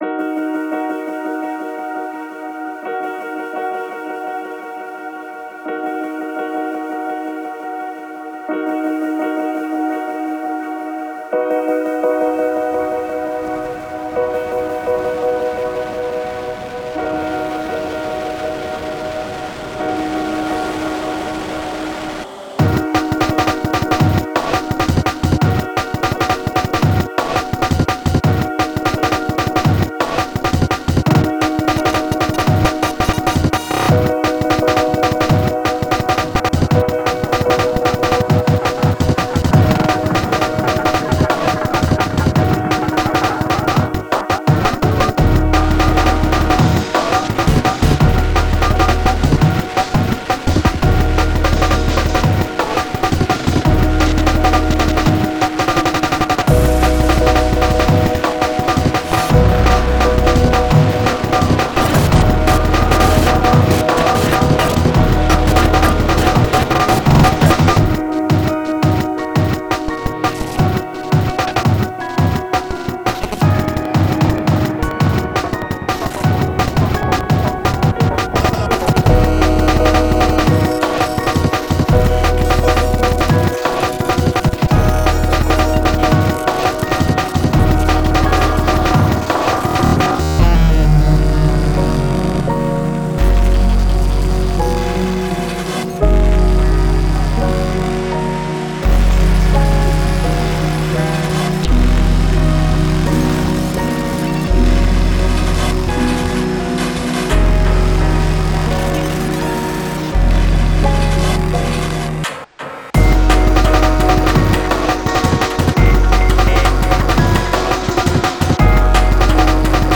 BRAINDANCE